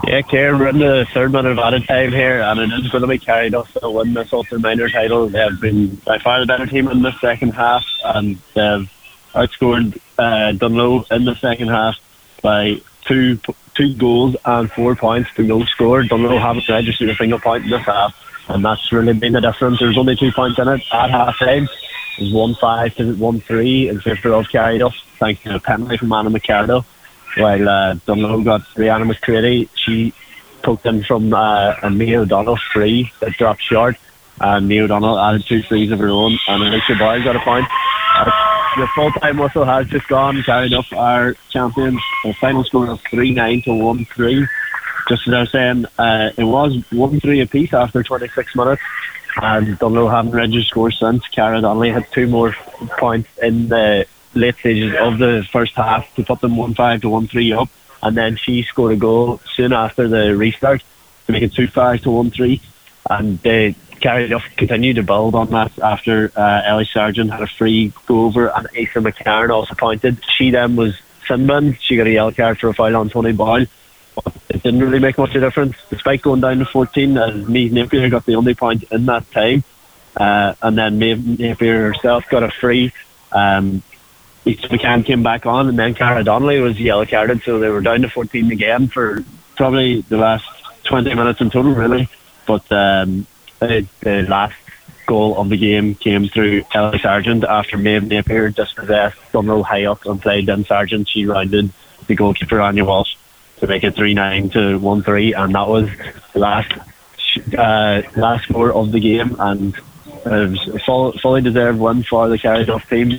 full time report